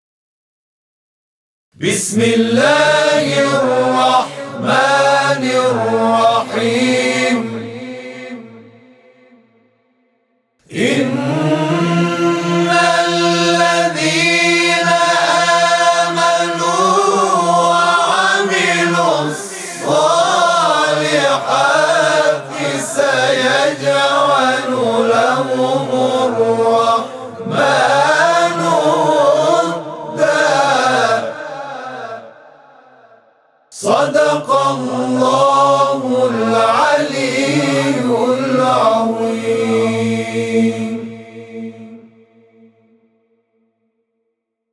صوت همخوانی آیه 96 سوره مریم(س) از سوی گروه تواشیح «محمد رسول‌الله(ص)»